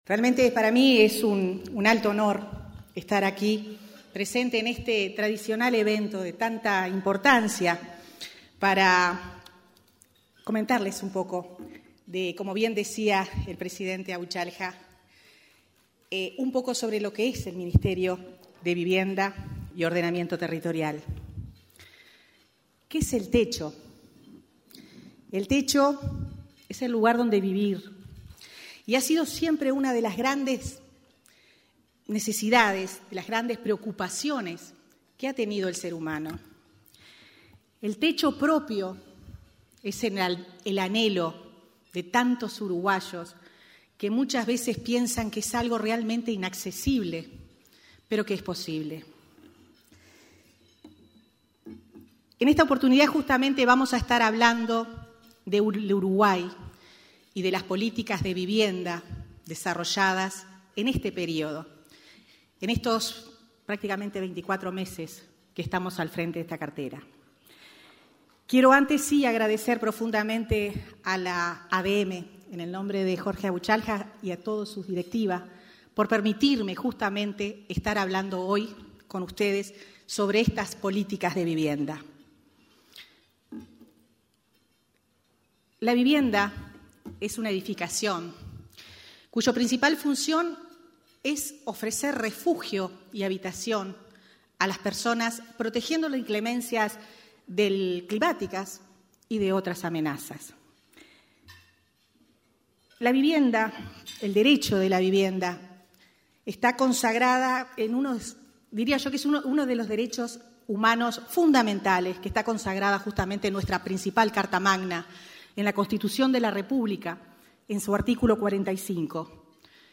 Palabras de la ministra de Vivienda y Ordenamiento Territorial, Irene Moreira
La ministra Irene Moreira participó, este 20 de julio, en el almuerzo de trabajo de la Asociación de Dirigentes de Marketing del Uruguay (ADM).